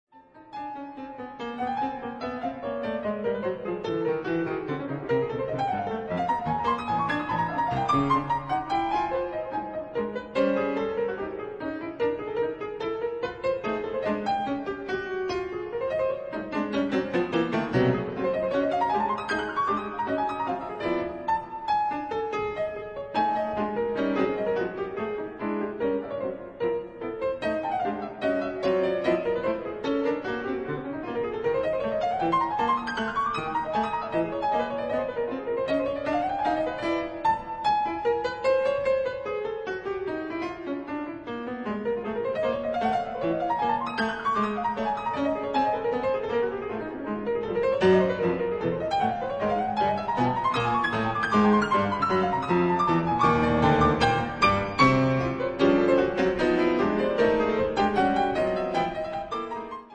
Composer, conductor, band leader, jazz pianist